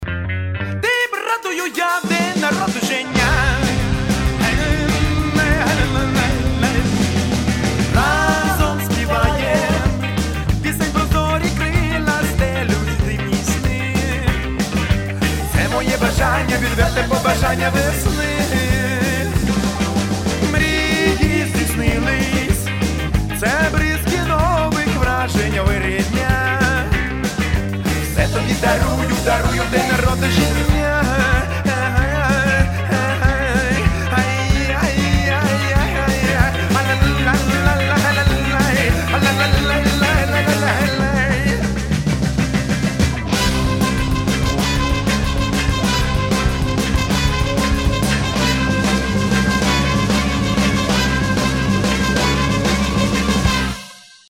• Качество: 320, Stereo
гитара
громкие
веселые